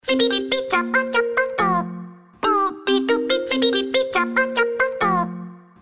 ремиксы , веселые , детский голос